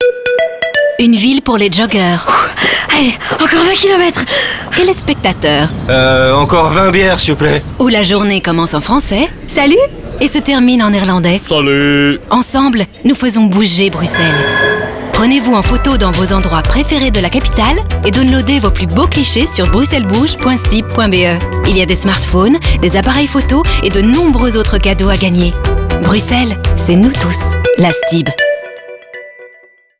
Radio: Sonicville
Spot radio FR 1